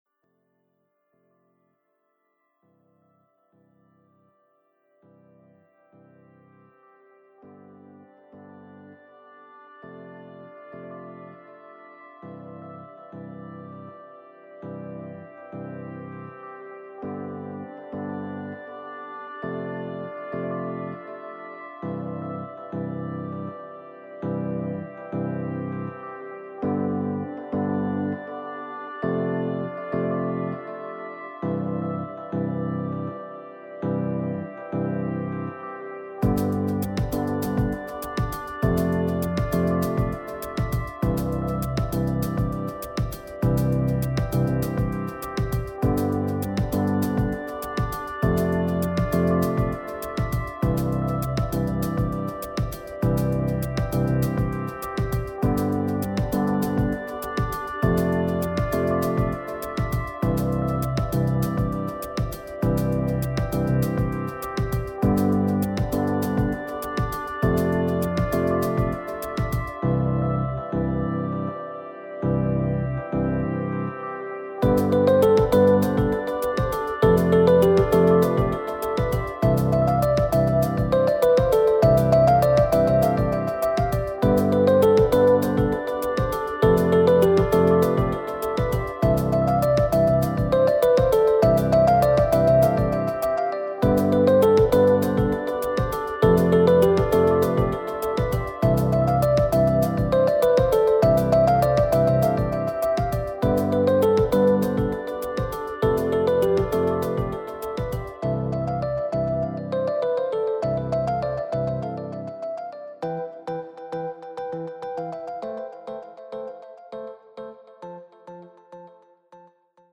uplifting electronic music